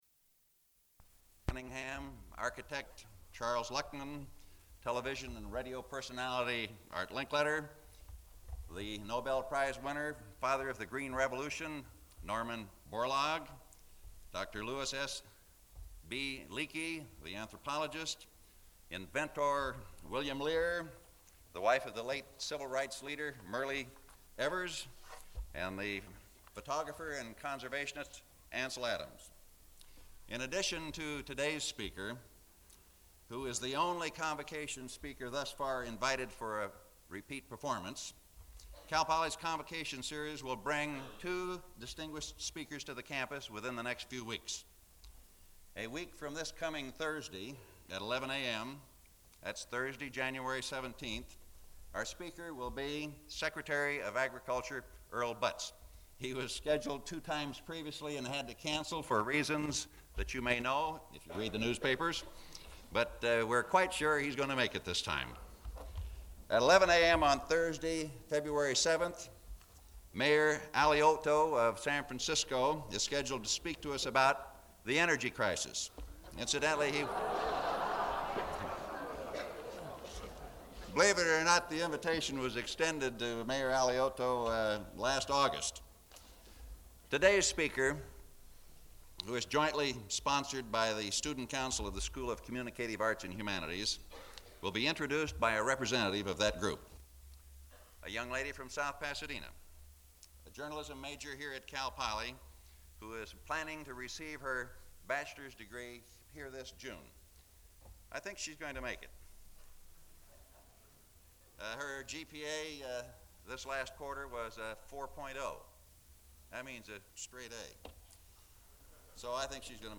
Download audio Metrics 11 views 12 downloads Citations: EndNote Zotero Mendeley Audio William Randolph Hearst Jr. Speech at Cal Poly Speech by William Randolph Hearst Jr. at the Cal Poly Campus. Speaks about his grandfather and father and his personal travels. At 00:08:09 the audio drops to a very low level, almost inaudible, until 00:08:36.
Open reel audiotape